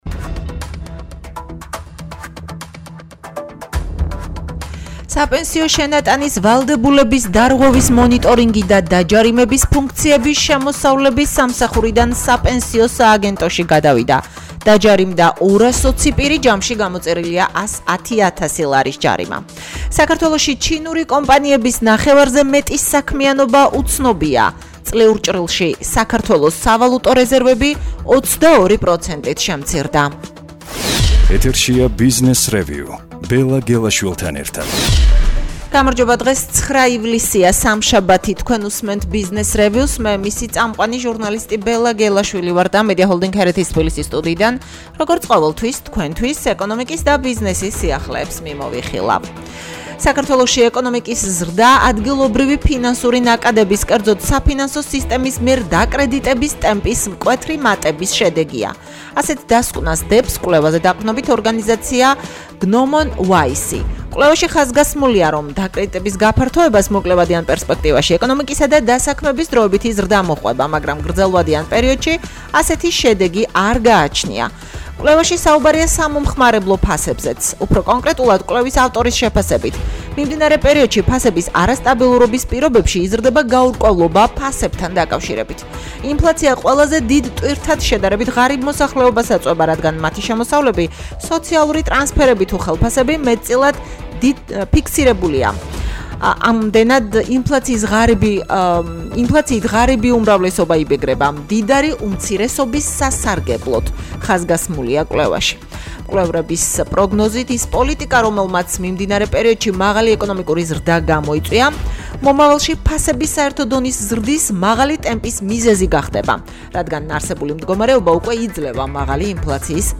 რადიოგადაცემა